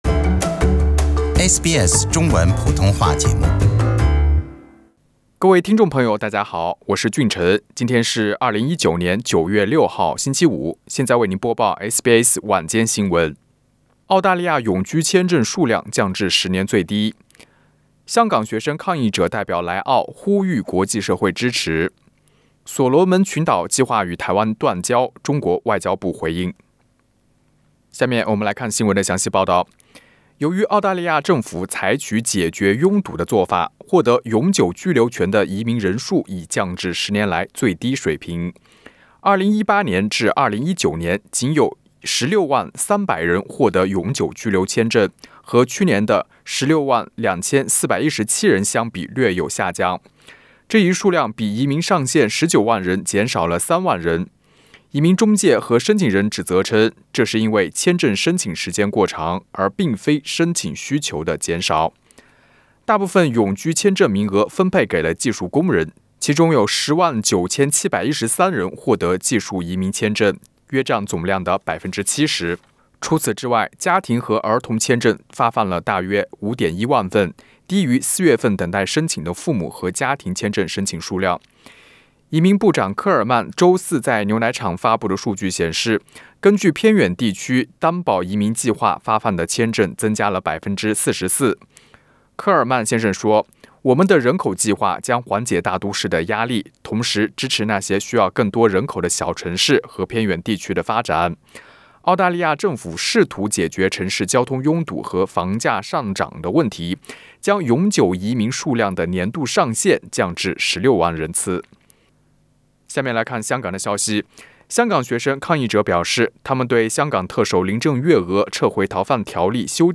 SBS Mandarin Evening News 0906